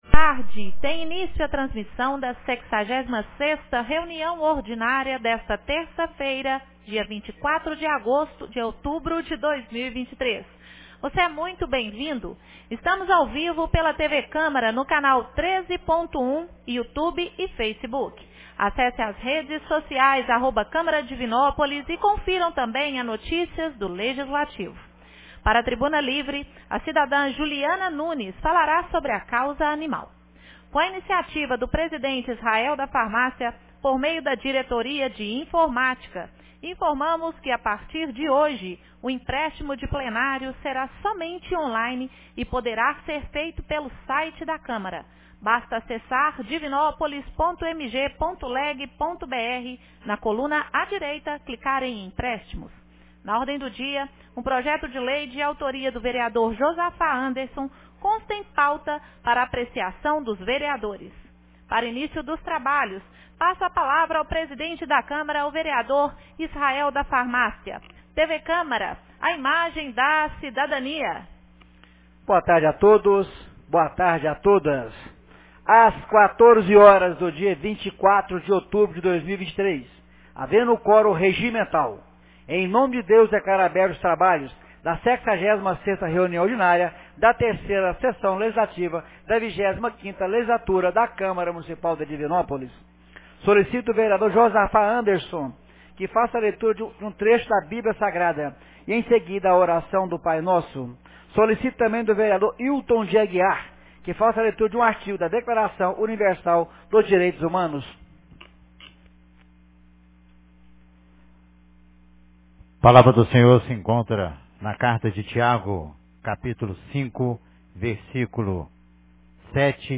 66ª Reunião Ordinária 24 de outubro de 2023